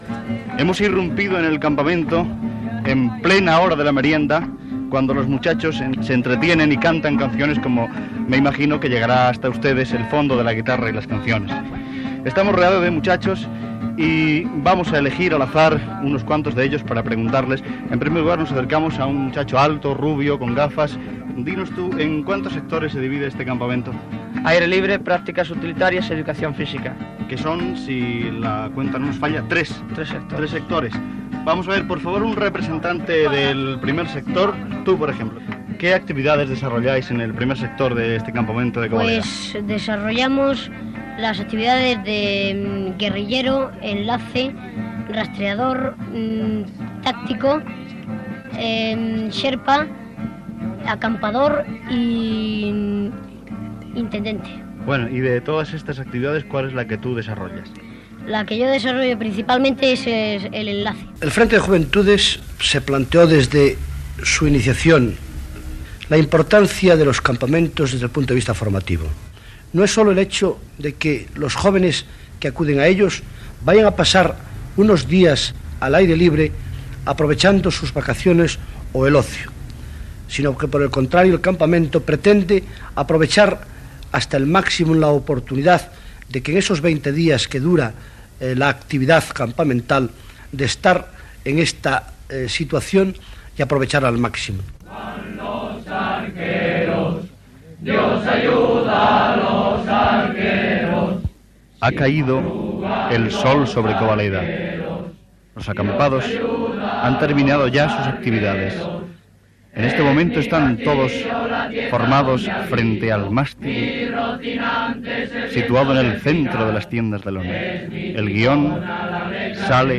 Reportatge fet en el Campamento Nacional de Juventudes Francisco Franco, organitzat pel Frente de Juventudes, a Covaleda (Soria)
Informatiu